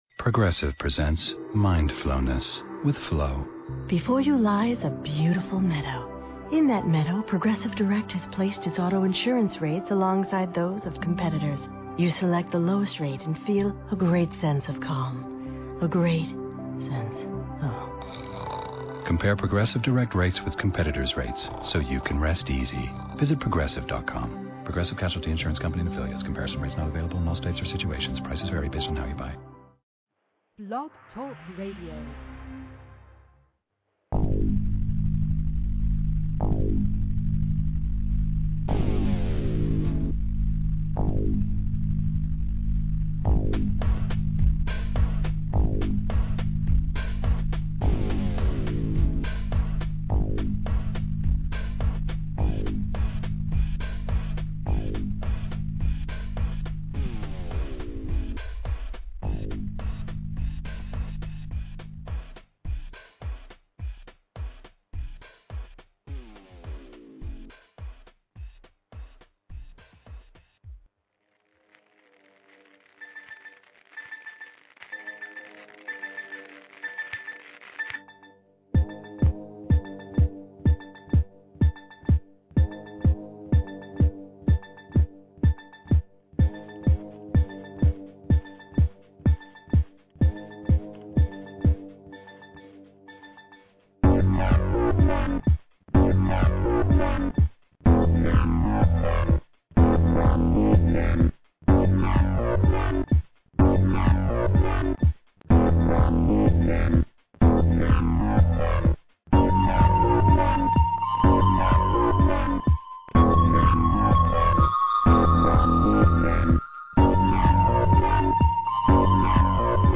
We discussed the vaccines link to spontaneous abortion and many other topics. My interview begins about 20 minutes in.…
This morning I remembered that I was on a radio show the night before the Don’t Inject Me Rally for Medical Freedom.